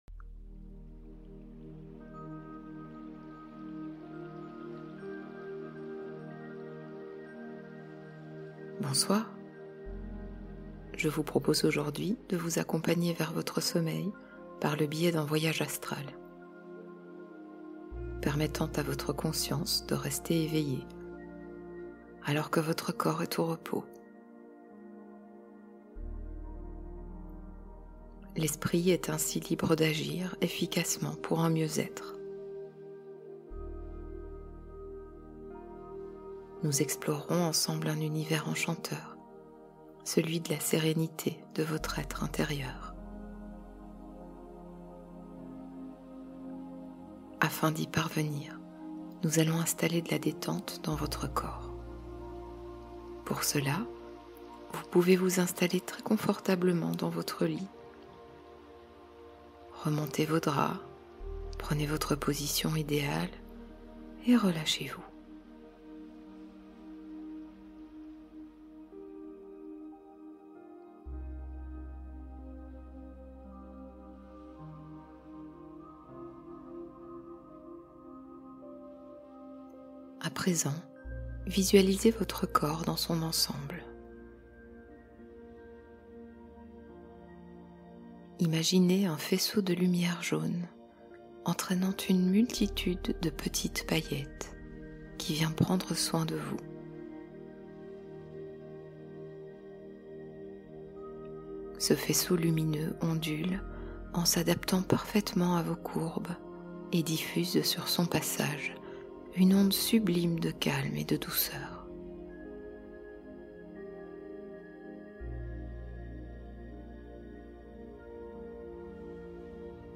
De la mer au sommeil : hypnose inspirée par l’univers marin